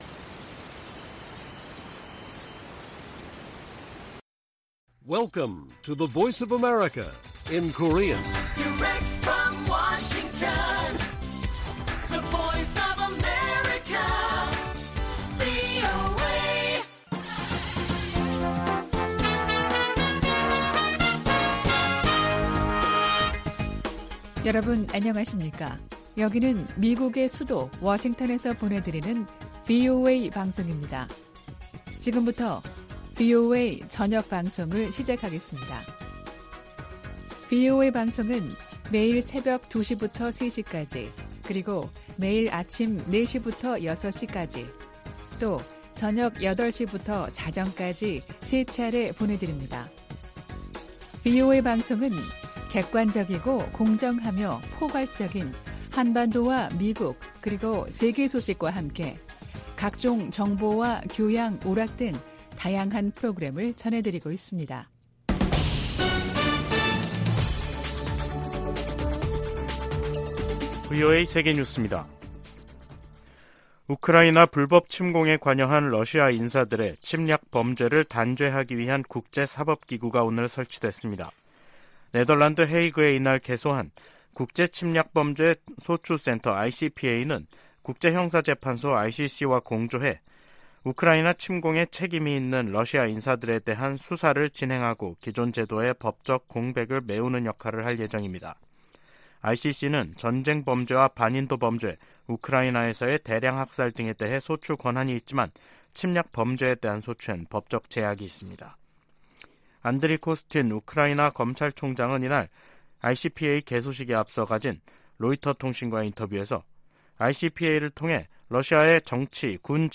VOA 한국어 간판 뉴스 프로그램 '뉴스 투데이', 2023년 7월 3일 1부 방송입니다. 최근 미국 의회에서는 본토와 역내 미사일 방어망을 강화하려는 움직임이 나타나고 있습니다.